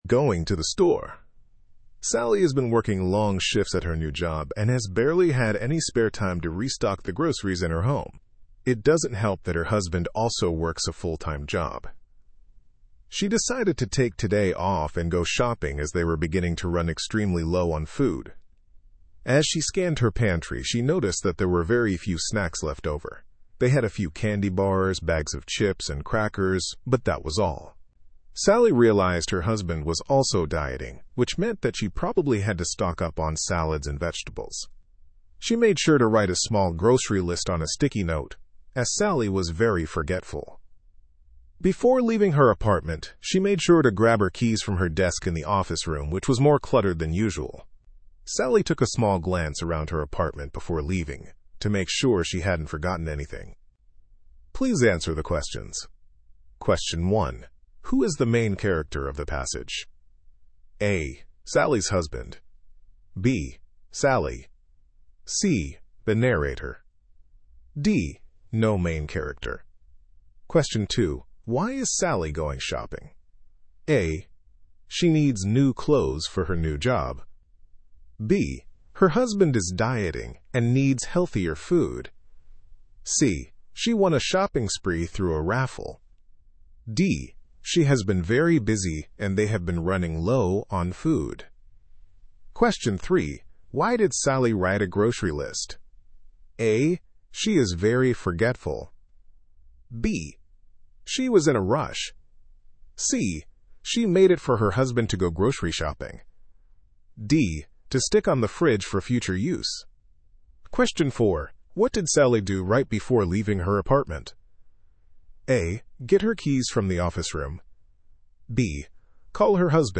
Estados Unidos